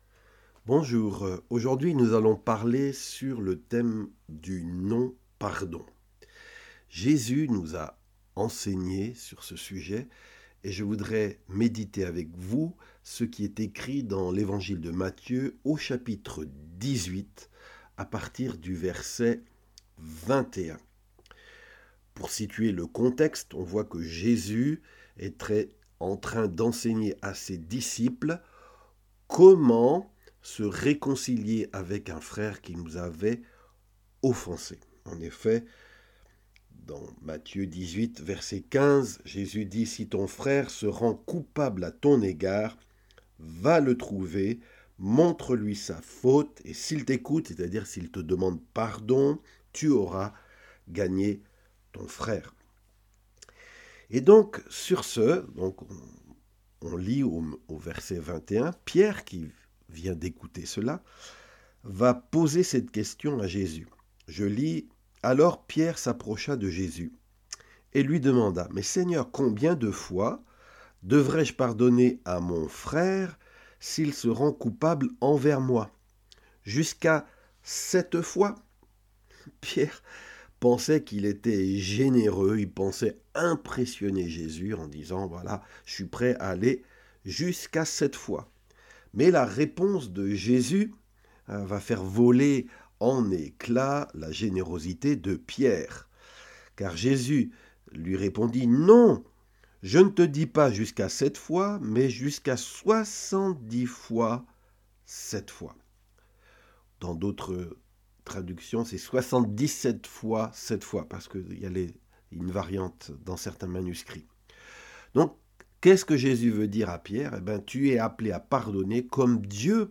Enseignement évangélique : la parabole du serviteur impitoyable